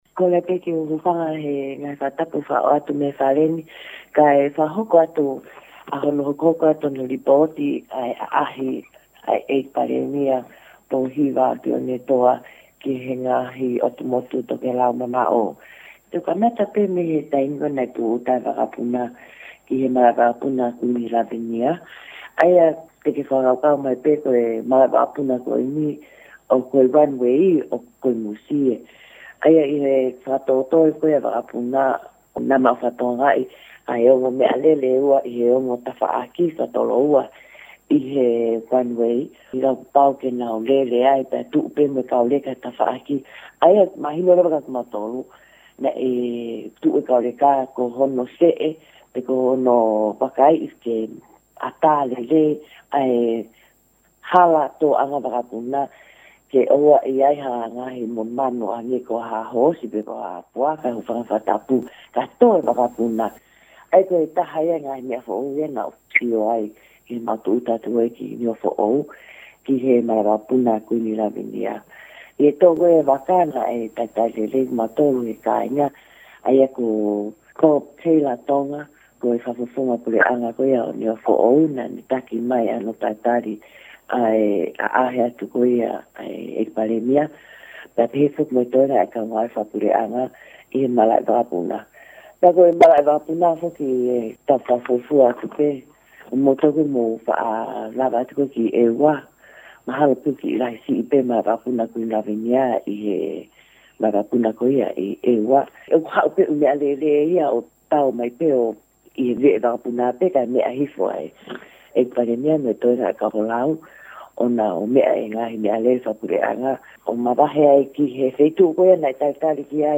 Audio of the Prime Minister’s meeting in Niuafo’ou was provided by the Prime Minister’s office and transcribed and translated into English by Kaniva News.